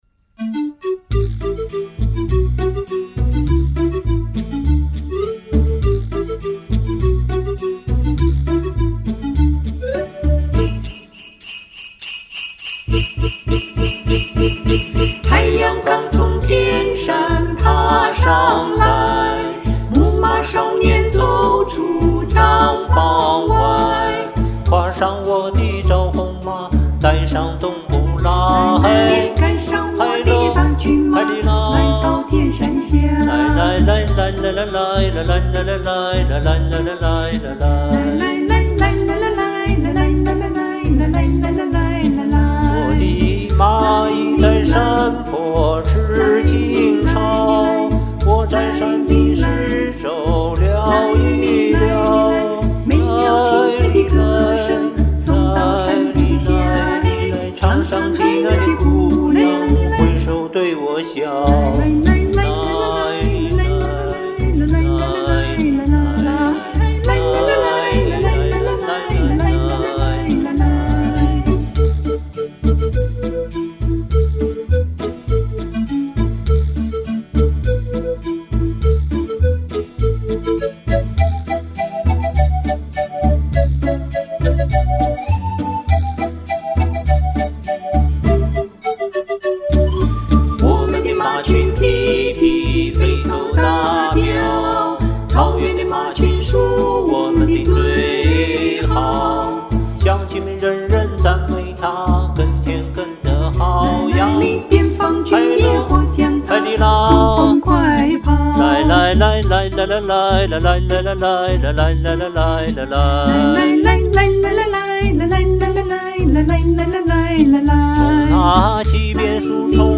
好听和谐的二重唱！
二位声音很搭，活泼欢快
漂亮的和声，优美欢快，非常好听。
感觉男声离听众近一点。
哦，记得男声和女声用的混响是同一种，也许是音质或录音设备不同